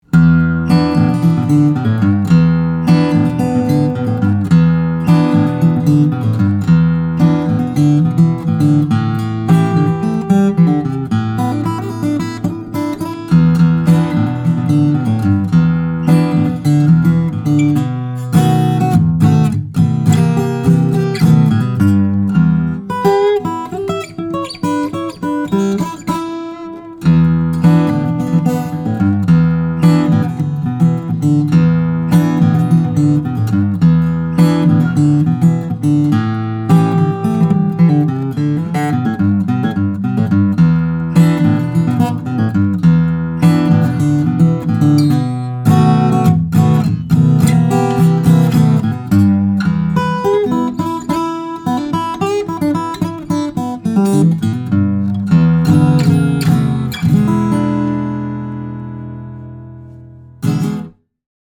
2018 Froggy Bottom H12 Limited, All-Koa - Dream Guitars
Bookmatched Koa back and sides are gorgeous, and then there’s bookmatched Koa on top too!
Froggy_Bottom_H12_KOA_PH.mp3